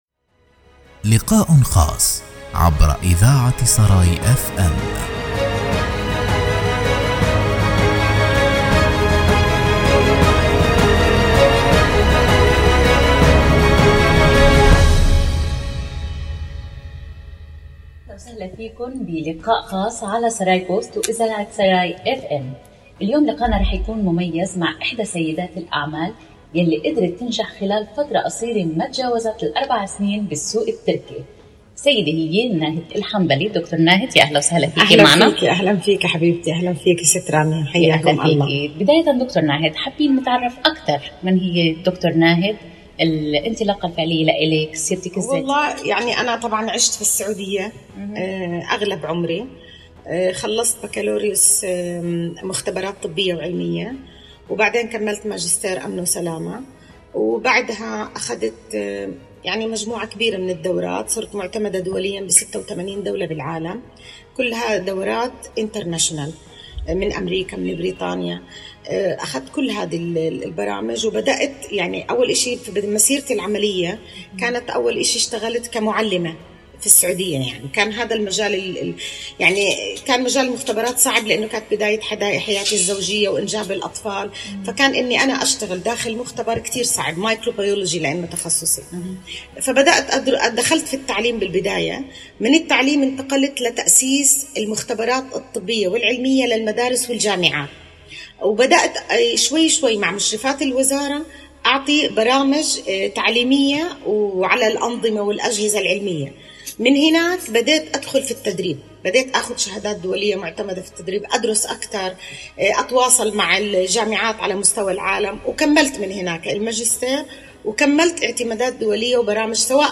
للإستماع للقاء كاملاً: